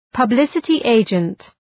publicity-agent.mp3